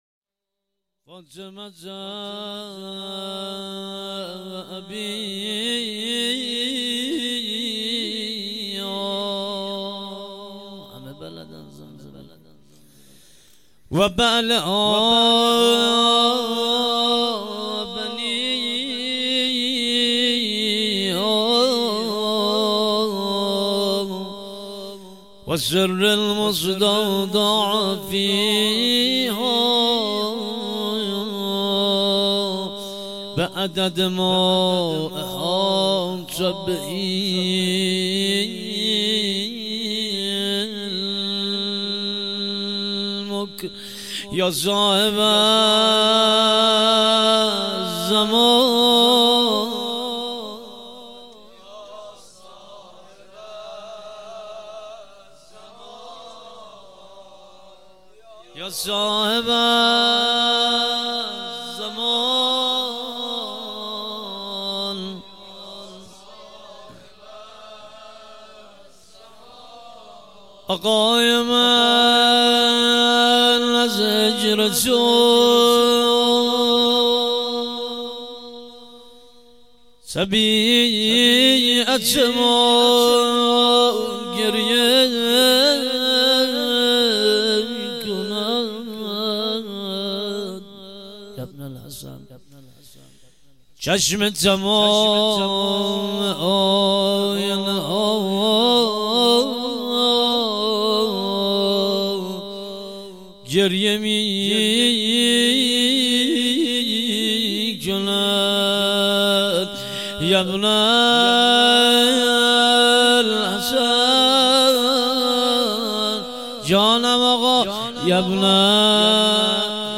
مراسم فاطمیه دوم 96.11.26